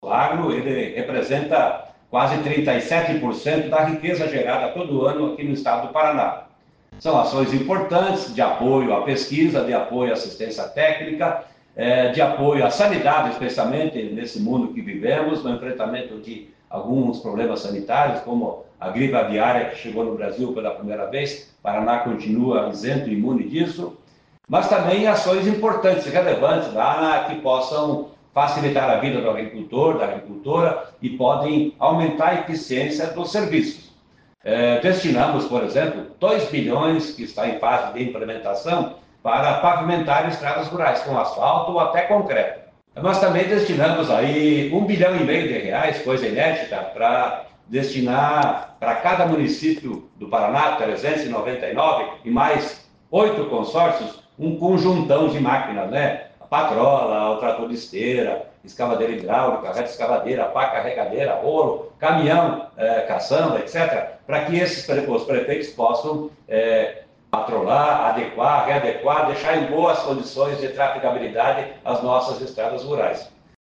Sonora do secretário da Fazenda, Norberto Ortigara, sobre o Paraná ser o estado que mais investiu na agricultura em 2025